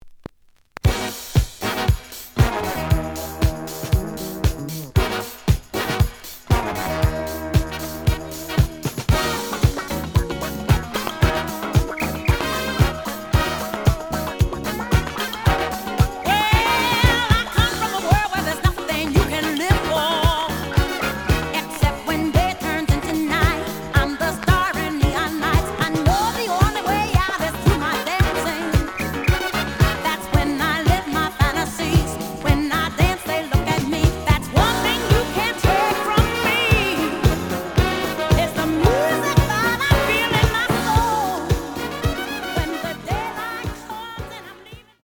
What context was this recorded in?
The audio sample is recorded from the actual item. Some click noise on B side due to scratches.